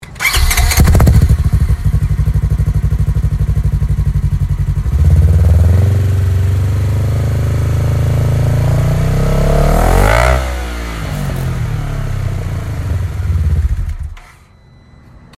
With an aggressive sound and increased flow for higher performance, the Polaris General Untamed Exhaust is exactly what you’ve been looking for.
• Low-tone, consistent sound quality